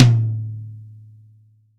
ROCK MD-TOM.WAV